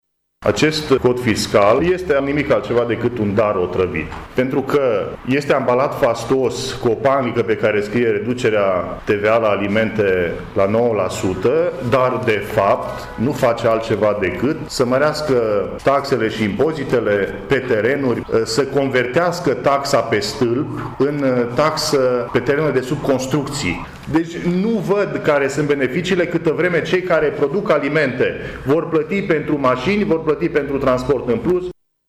Este punctul de vedere prezentat astăzi, într-o conferință de presă, de senatorul PNL Marius Pașcan.